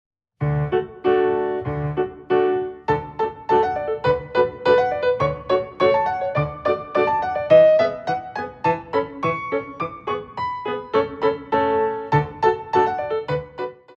Ballet class music for first years of ballet